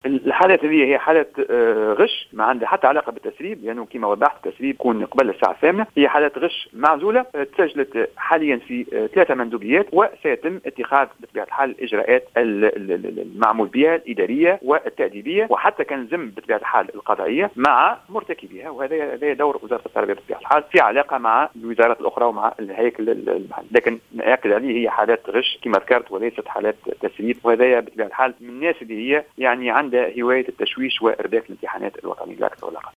Dans une déclaration accordée à Jawhara FM ce mercredi 6 juin 2018